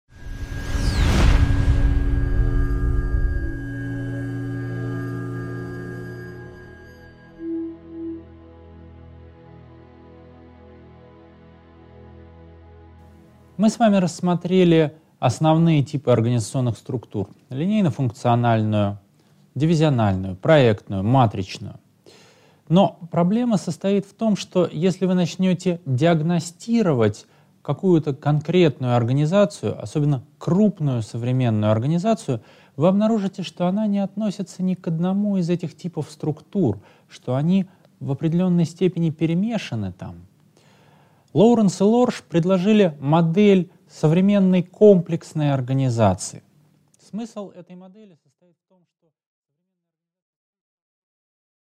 Аудиокнига 3.4. Современные комплексные организации | Библиотека аудиокниг